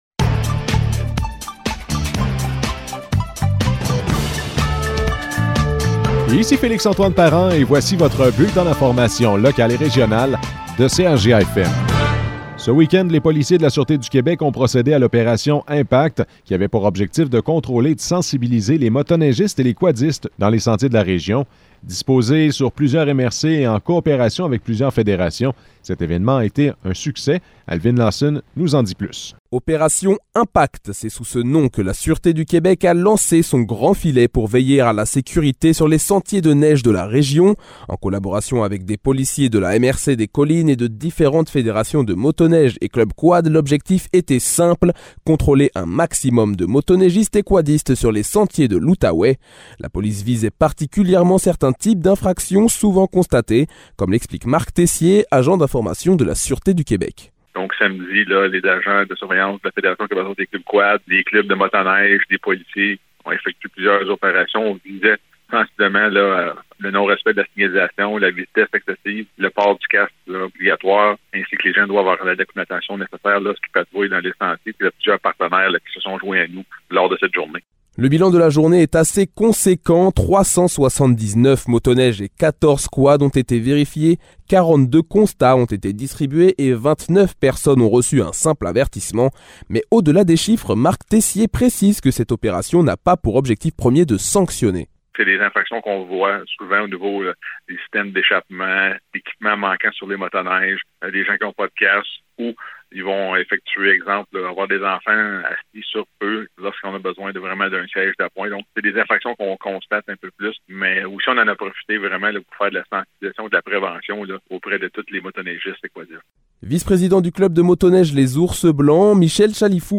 Nouvelles locales - 8 février 2022 - 12 h